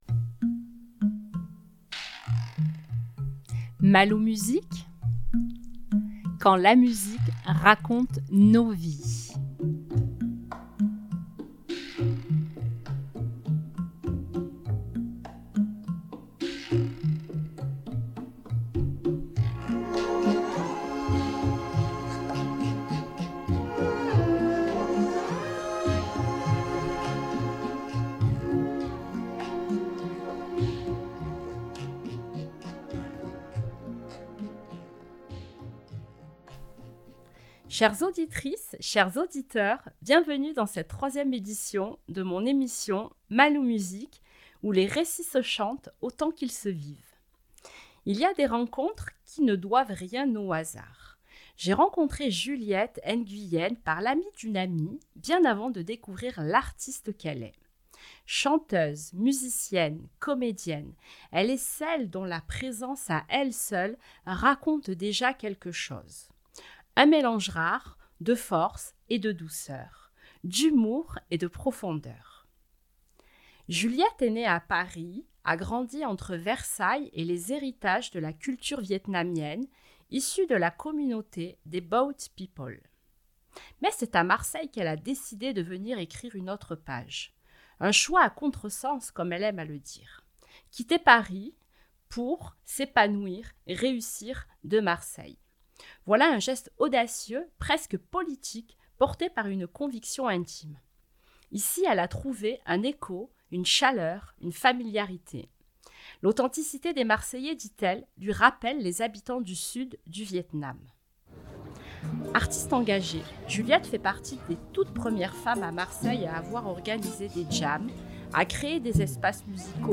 épisode n°3 Lundi 30 Juin 2025 Émission mensuelle, où musique, rencontres et histoires s'entrelacent pour donner vie à des causeries inattendues et inspirantes. Chaque témoignage est personnel, une sélection musicale sur mesure, une mise en son immersive.